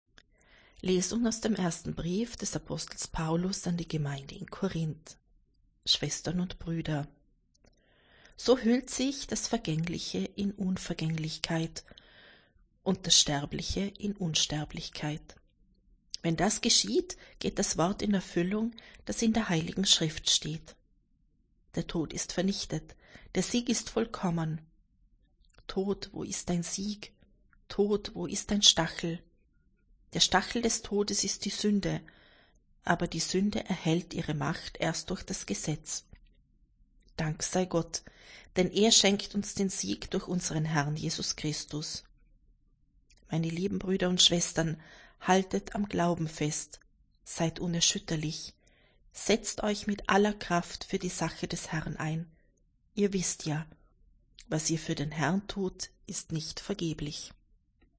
Wenn Sie den Text der 2. Lesung aus dem Brief des Apostels Paulus an die Gemeinde in Korínth anhören möchten: